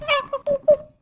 sounds_monkey_01.ogg